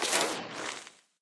Minecraft Version Minecraft Version snapshot Latest Release | Latest Snapshot snapshot / assets / minecraft / sounds / mob / creeper / death.ogg Compare With Compare With Latest Release | Latest Snapshot
death.ogg